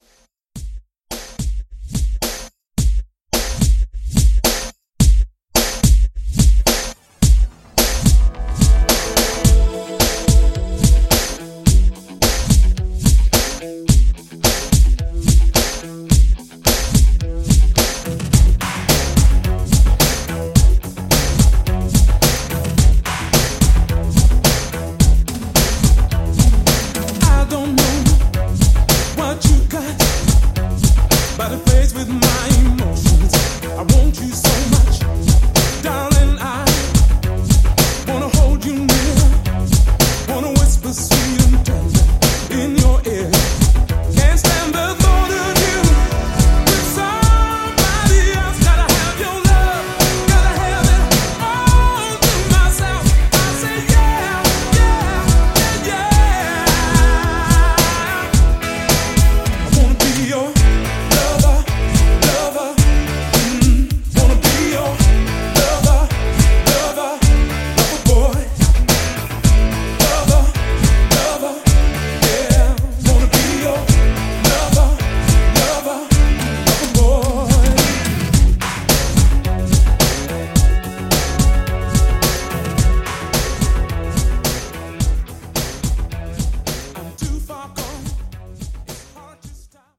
Genre: 80's Version: Clean BPM: 108 Time